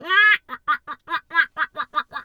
duck_2_quack_seq_04.wav